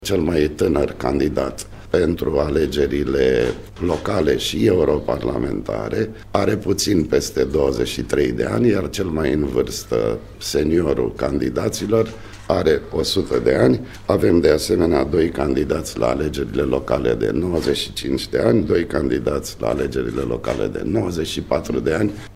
Totodată, românii își vor alege şi cei 33 de reprezentanţi în Parlamentul European. 5 dintre candidați s-au născut la începutul secolului trecut, după cum a spus preşedintele Autorităţii Electorale Permanente, Toni Greblă.